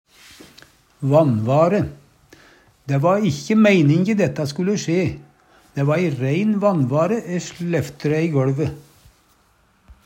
vanvare - Numedalsmål (en-US)